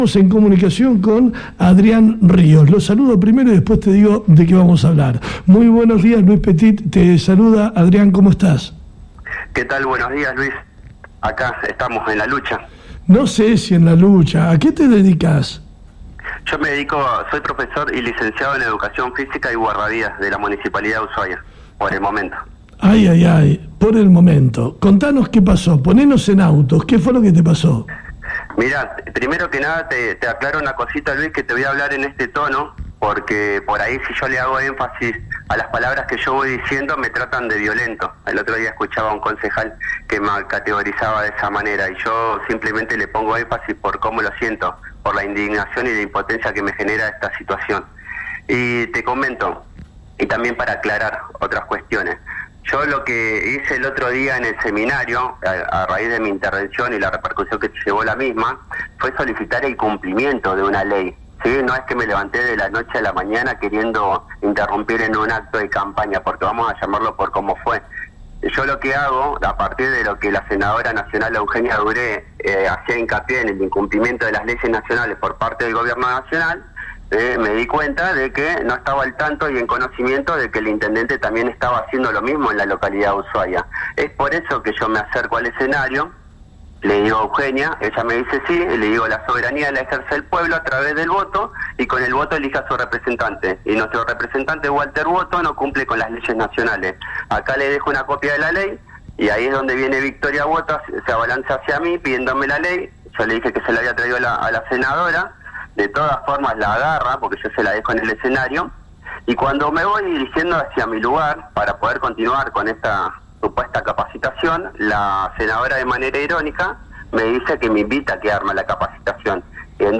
Entrevistamos en FM Ártika al guardavidas municipal